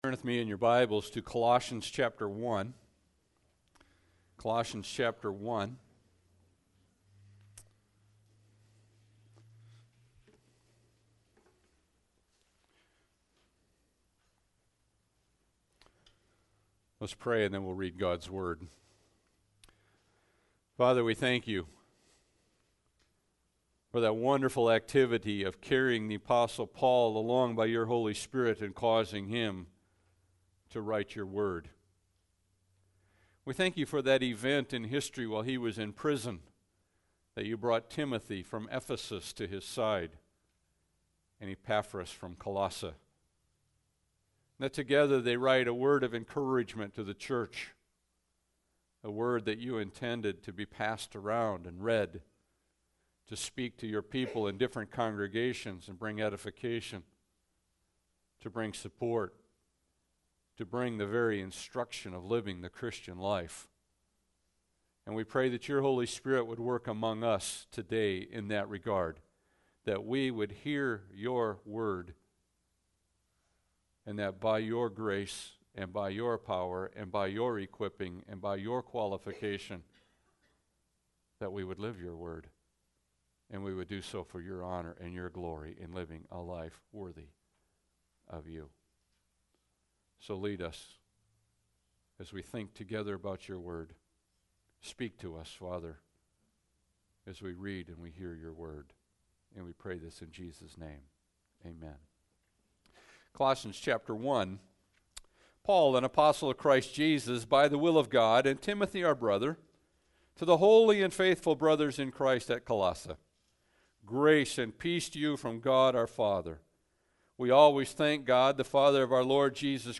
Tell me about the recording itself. Passage: Colossians 1:9-13 Service Type: Sunday Service